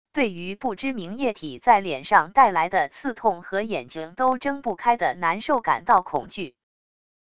对于不知名液体在脸上带来的刺痛和眼睛都睁不开的难受感到恐惧wav音频